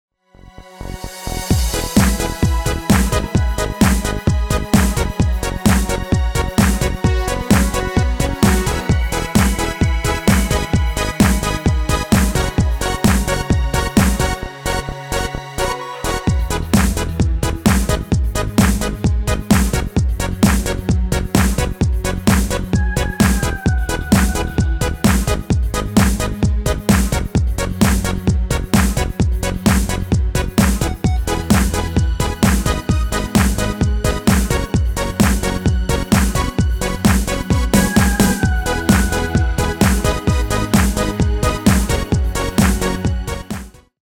Demo/Koop midifile
Genre: Pop & Rock Internationaal
Toonsoort: E
- Vocal harmony tracks